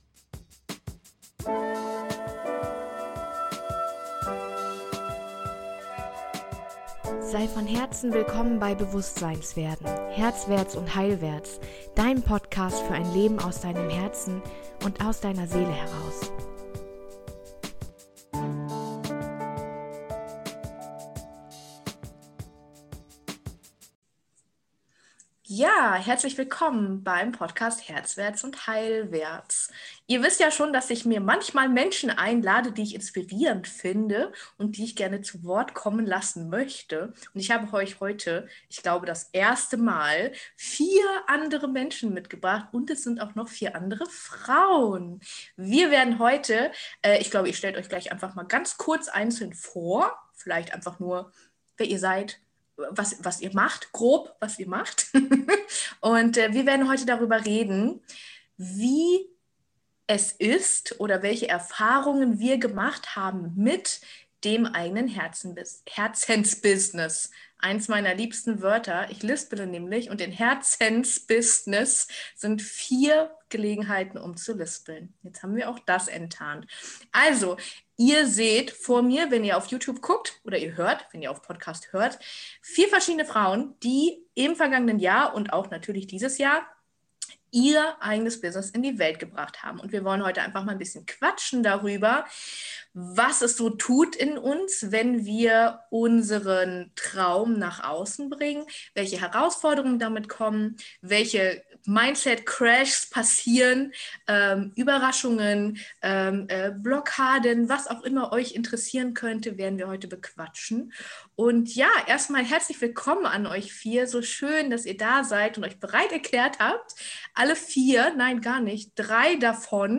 In dieser Folge interviewe ich vier selbstständige Frauen in ihrem Herzensbusiness. Wir sprechen über Unternehmermindset, Blockaden und Struggles und die unglaubliche Freiheit, die sich durch onlineBusiness einstellen kann!